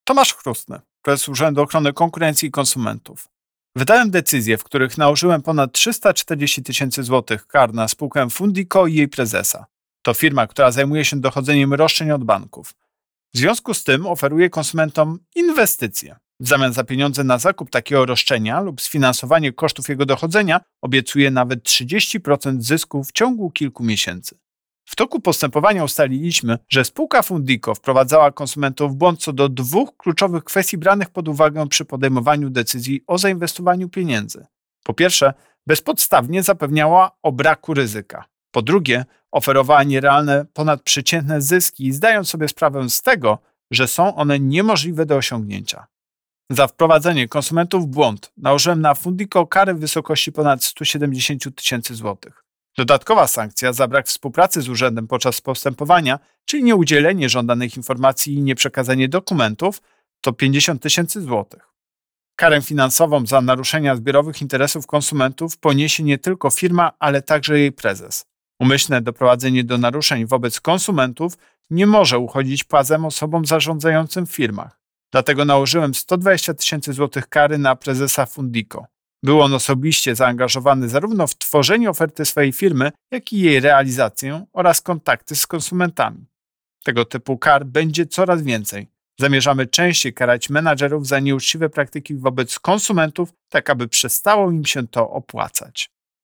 Pobierz wypowiedź Prezesa UOKiK Tomasza Chróstnego